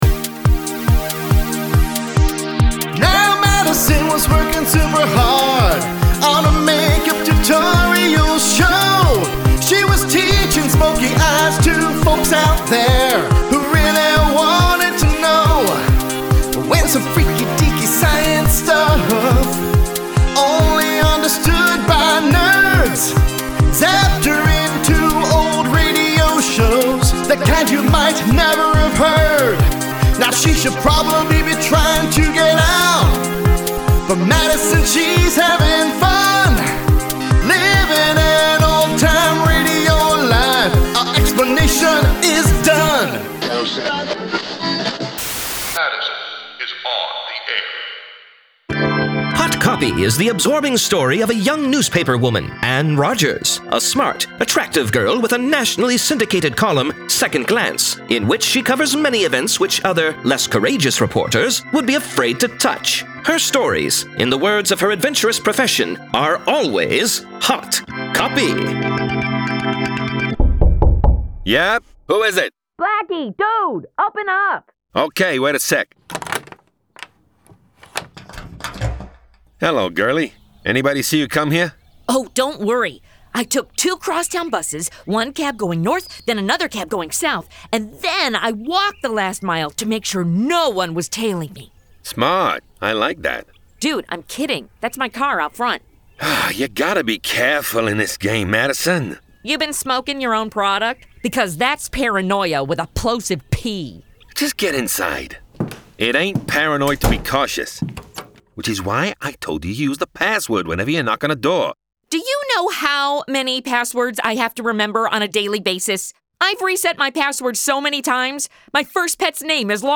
Follow Madison Standish, a modern day influencer, as she gets zapped back into the Golden Age of Radio. Actual OTR scripts adapted!
Audio Drama